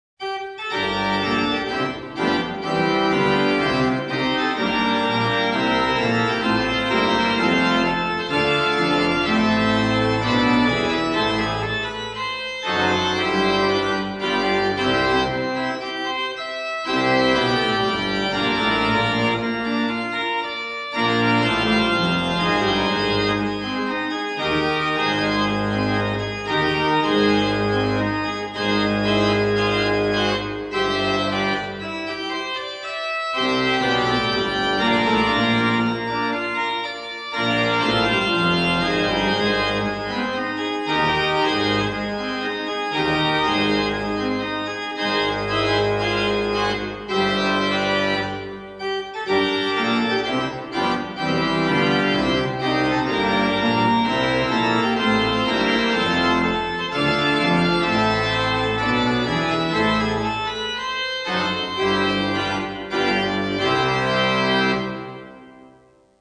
Die neue Orgel in der Pfarrkirche Mauthausen
mechanische Traktur (Schleifladen), 22 Register, Normalkoppeln, Tremulant, 2 Manuale (Brüstungs-Hauptwerk, schwellbares Positiv), Pedalwerk
orgel.mp3